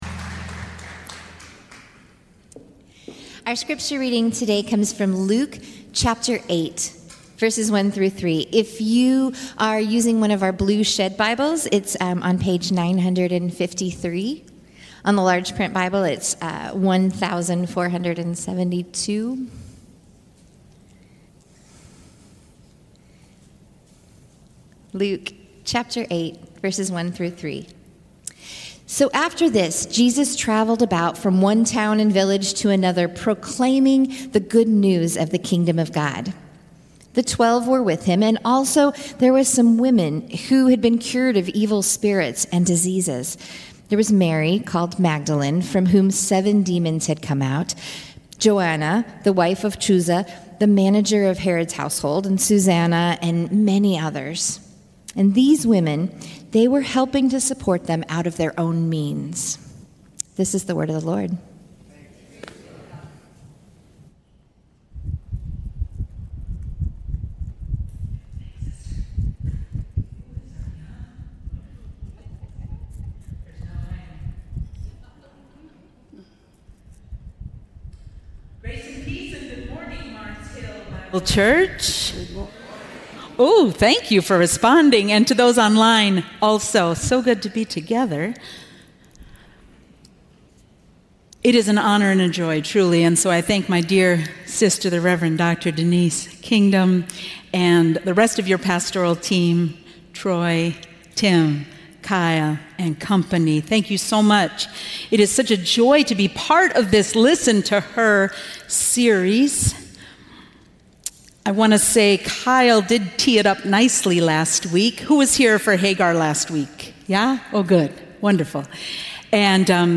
The women who traveled with Jesus have often been sidelined and misrepresented. This Teaching invites us to listen again to the lives of the women who were deacons and disciples of Jesus during his earthly ministry.